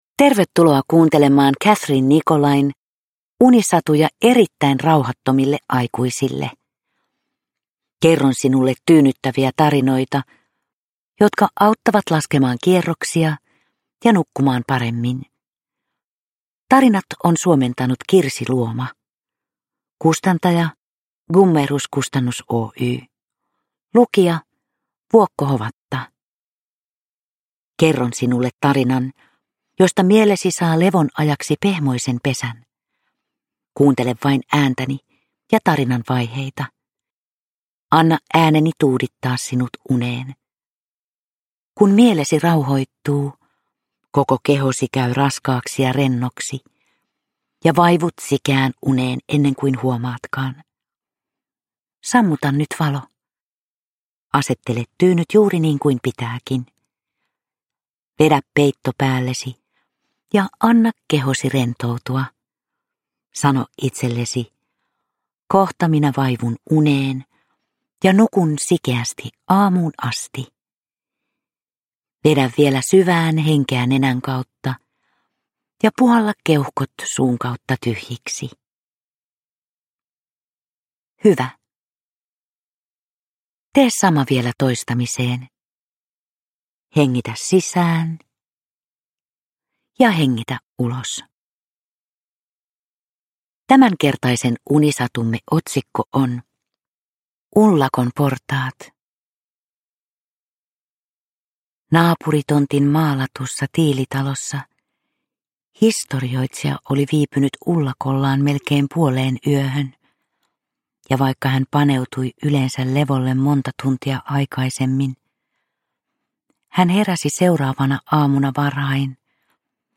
Vuokko Hovatan tyyni ääni saattelee kuulijan lempeästi unten maille.
Uppläsare: Vuokko Hovatta